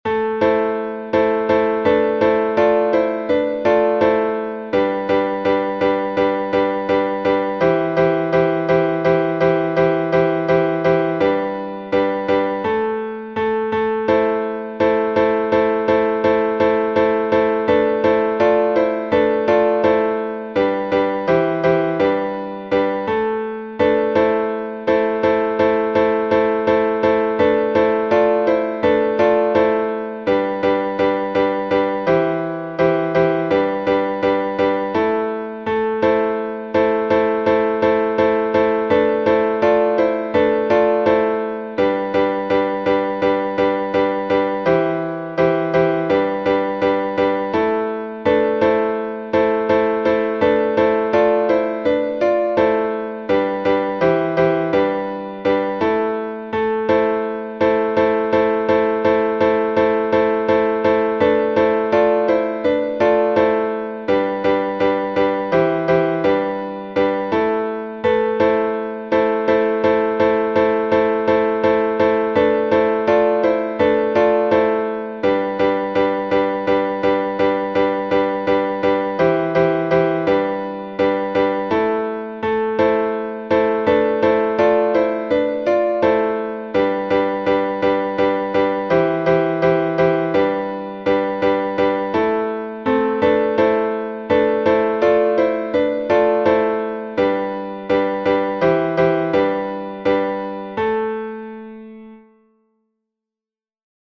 Старинный напев